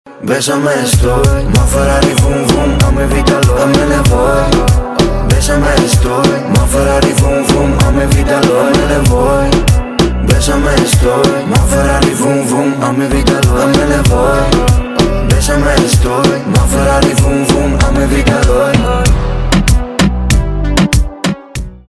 2025 » Новинки » Русские » Поп Скачать припев